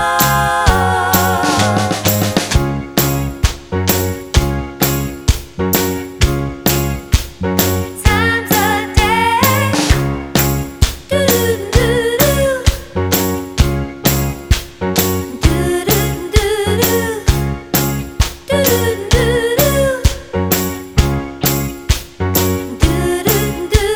With Chord Soul / Motown 2:46 Buy £1.50